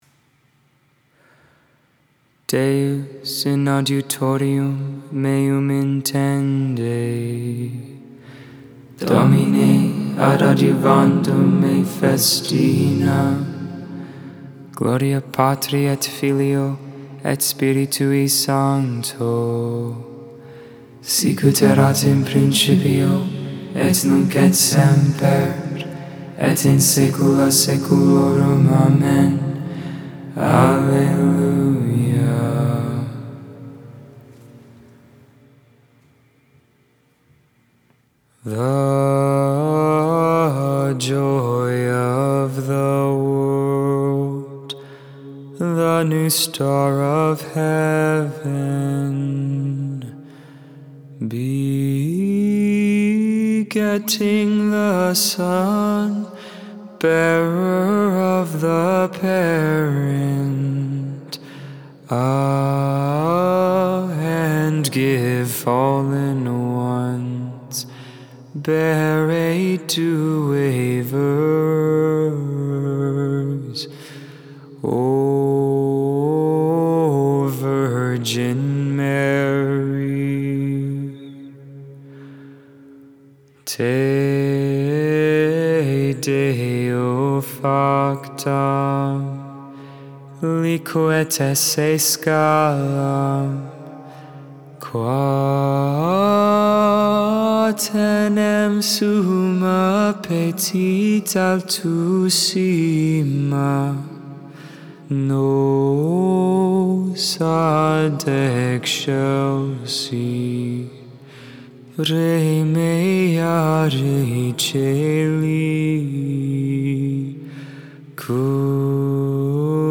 Vespers, Evening Prayer for the 20th Monday in Ordinary Time, August 15th, 2022. Solemnity of the Assumption.